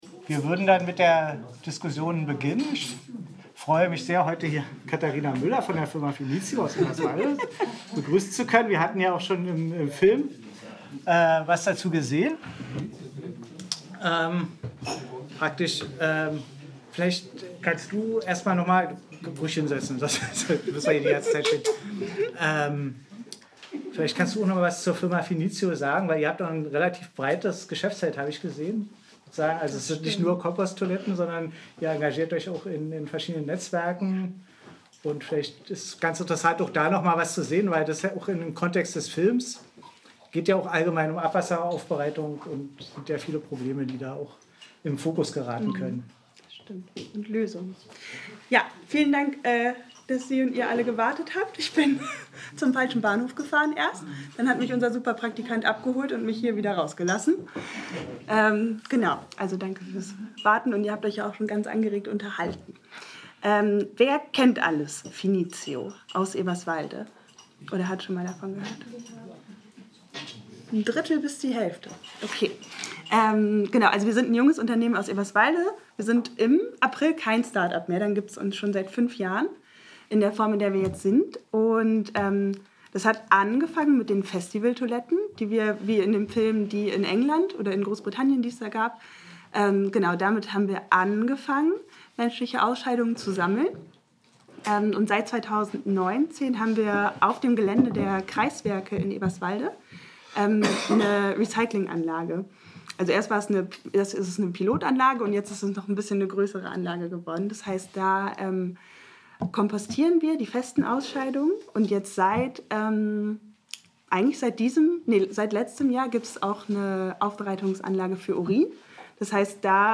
Publikumsgespräch